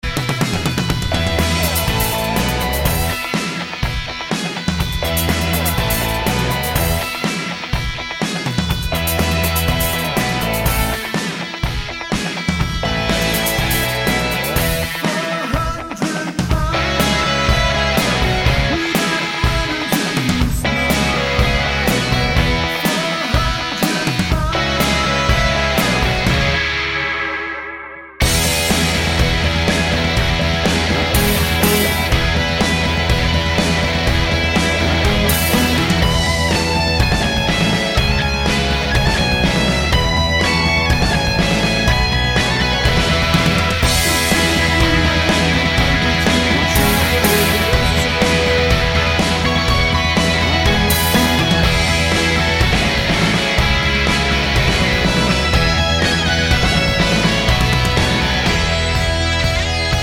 no Backing Vocals Pop (1980s) 3:15 Buy £1.50